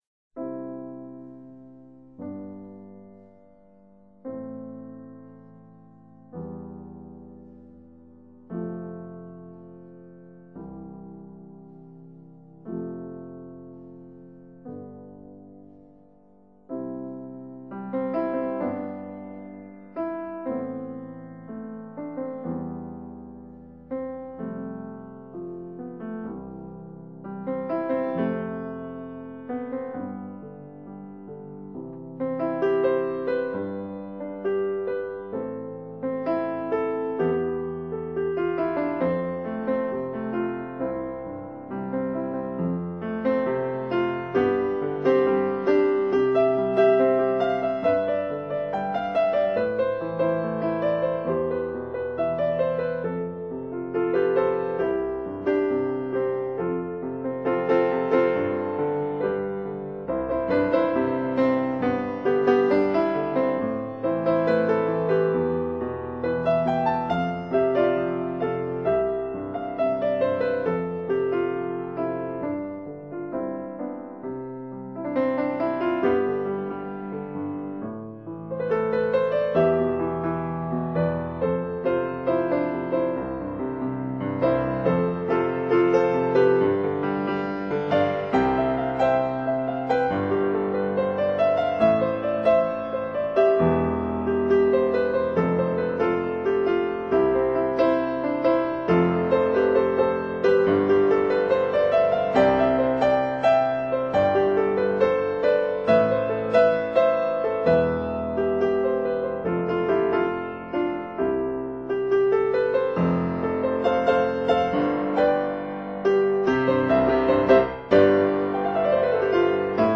Pop-Jazz arrangement